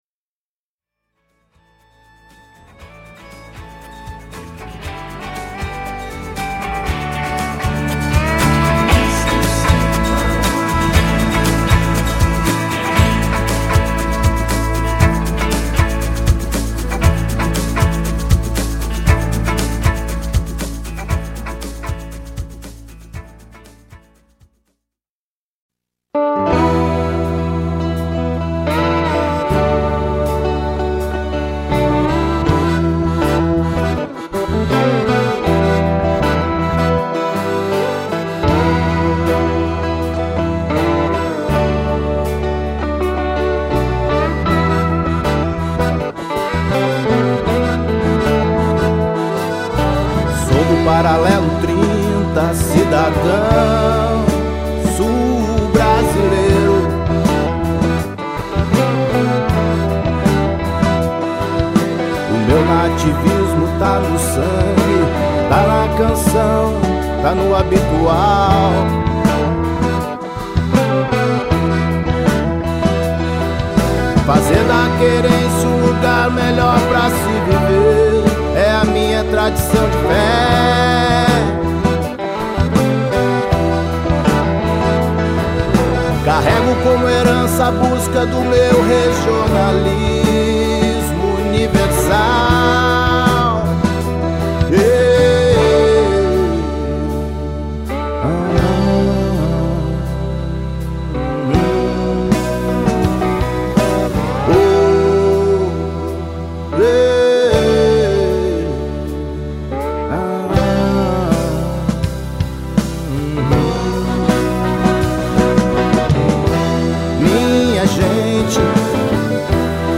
Ou seja, consegui unir elementos que me emocionam muito no universo musical, como o blues, a milonga, o folk, o rock, a viola e o slide.